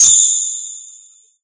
Ice3.ogg